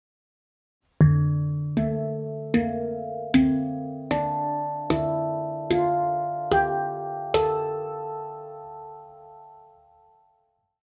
Moon II Handpan D-Integral / Kurd
Kurd/Integral gir et varmt, harmonisk og balansert lydbilde som oppleves intuitivt og enkelt å spille på.
Instrumentet er laget av rustfritt stål, noe som gir en klar tone, lang sustain og balanserte overtoner.
D3, A3, Bb3, C4, D4, E4, F4, G4, A4
• Frekvens: 440 Hz